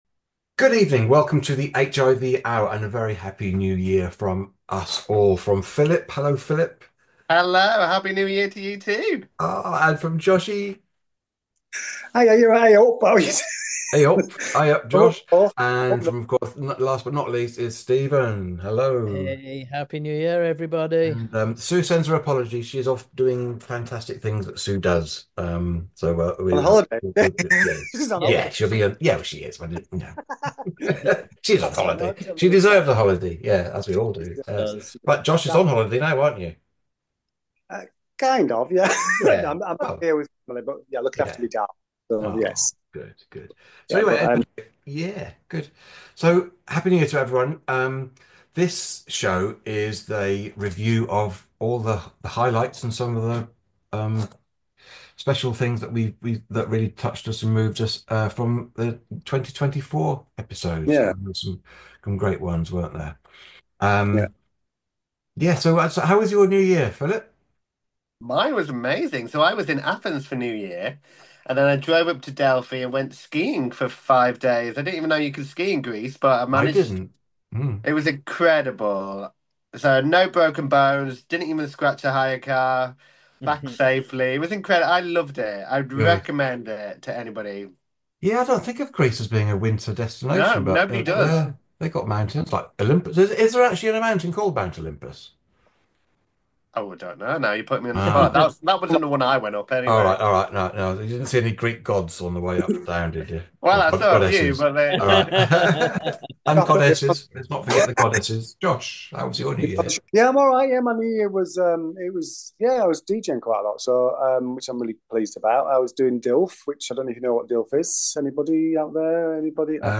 via Zoom for a look back at the highlights of 2024.